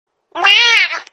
cat-meow.mp3